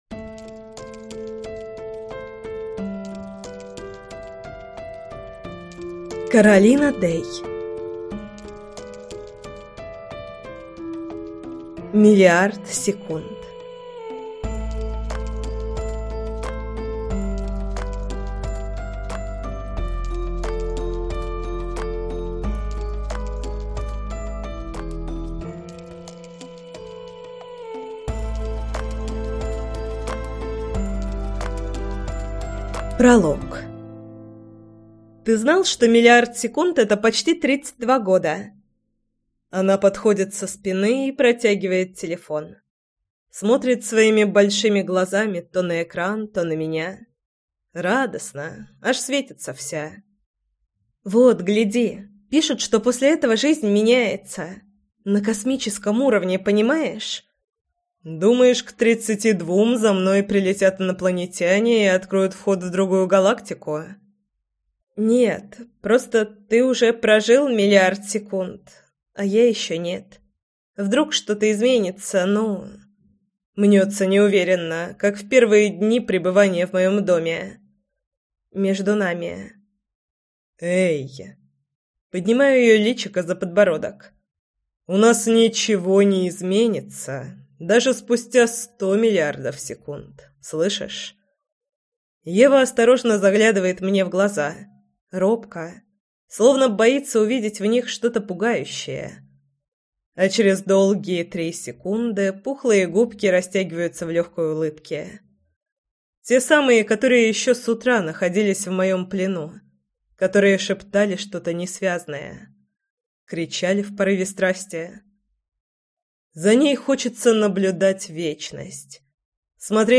Аудиокнига Миллиард секунд | Библиотека аудиокниг